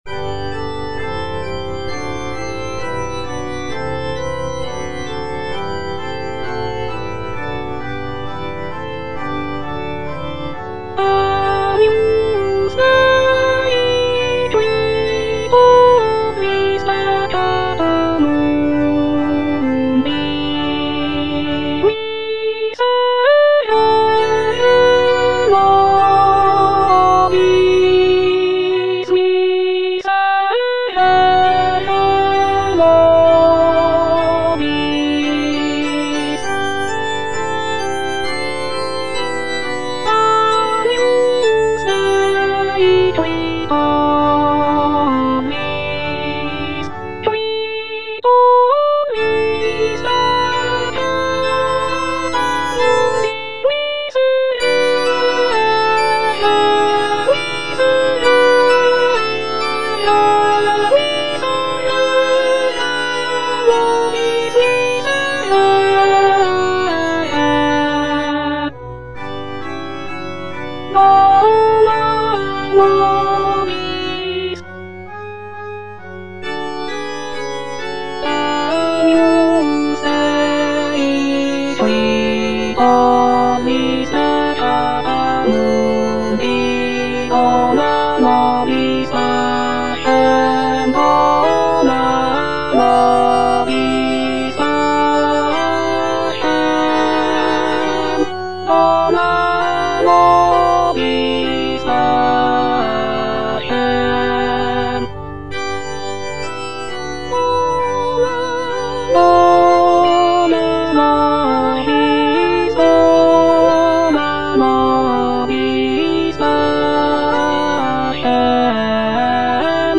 (alto I) (Emphasised voice and other voices) Ads stop